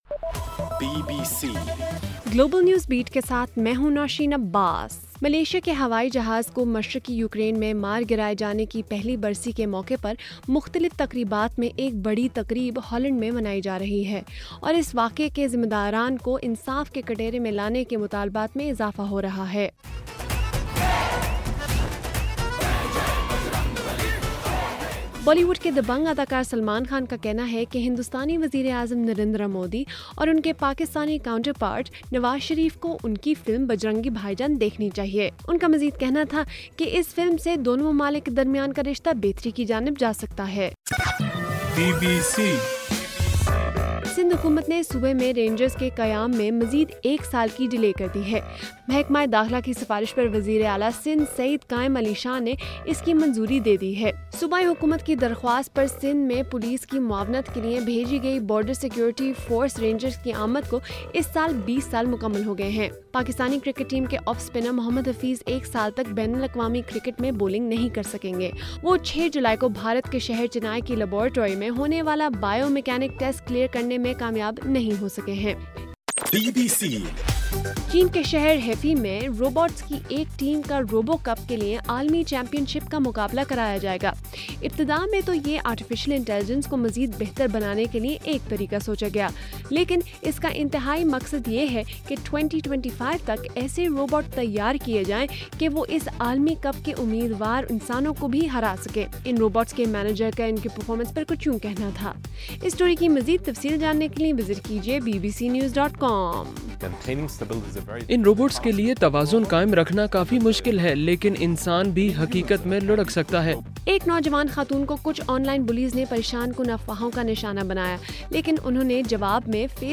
جولائی 17: رات 12 بجے کا گلوبل نیوز بیٹ بُلیٹن